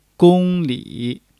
gong1--li3.mp3